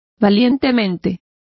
Complete with pronunciation of the translation of bravely.